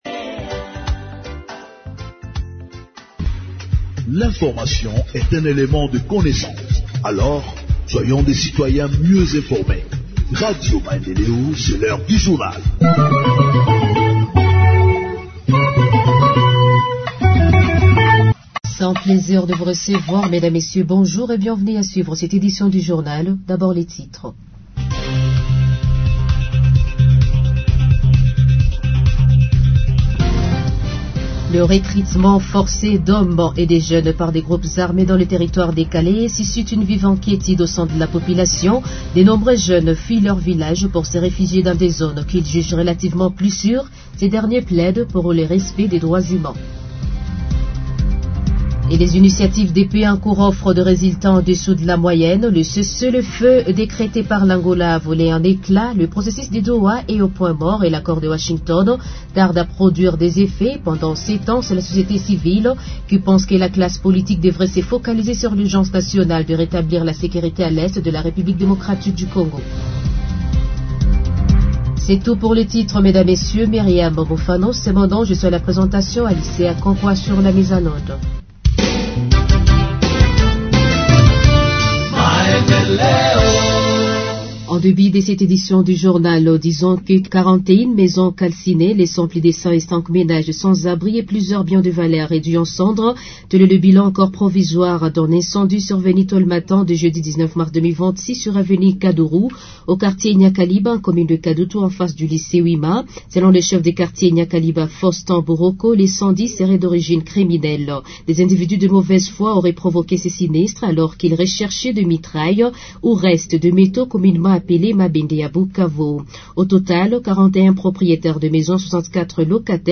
Journal en Français du 19 Mars 2026 – Radio Maendeleo